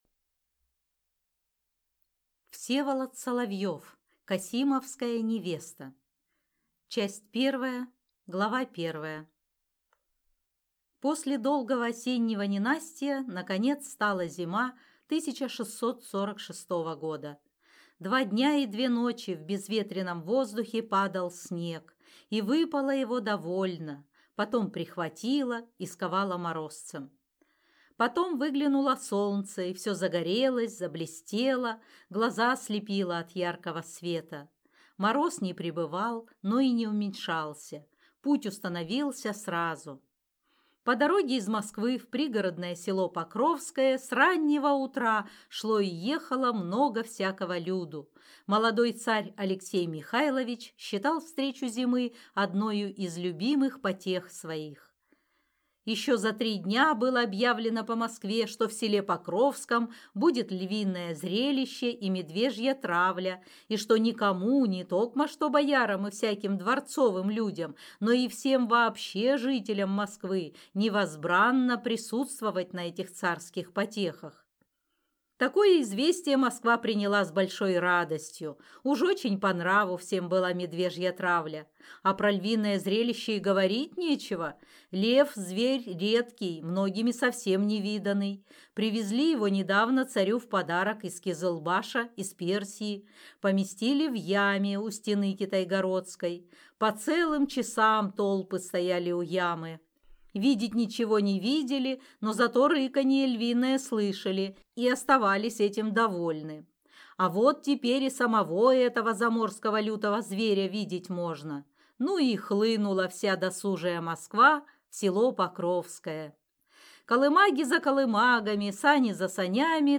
Аудиокнига Касимовская невеста | Библиотека аудиокниг